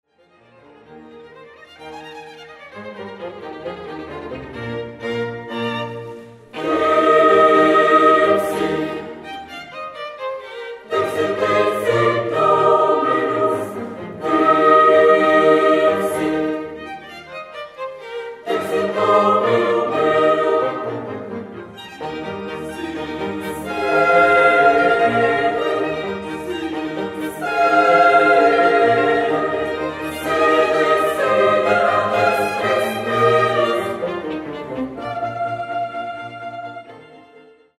per soli, coro femminile, orchestra d'archi e basso continuo